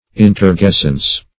Search Result for " inturgescence" : The Collaborative International Dictionary of English v.0.48: Inturgescence \In`tur*ges"cence\, n. [L. inturgescens, p. pr. of inturgescere to swell up.